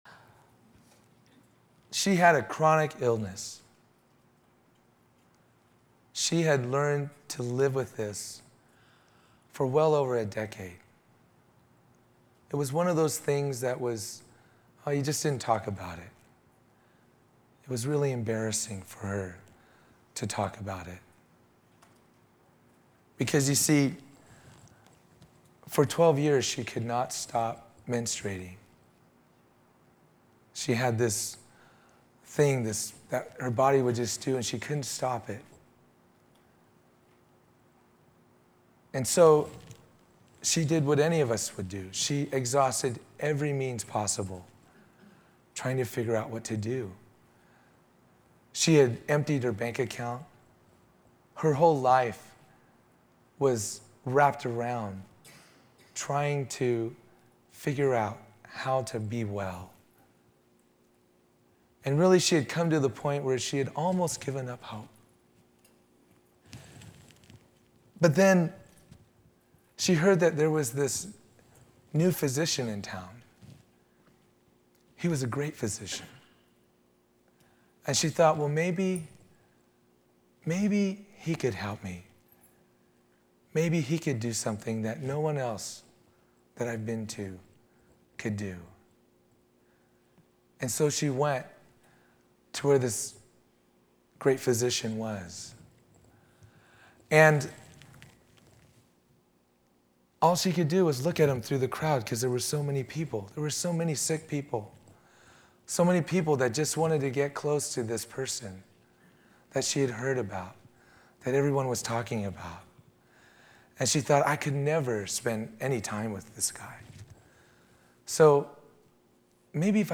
Series: 2003 Calvary Chapel Mid-South Music And Worship Conference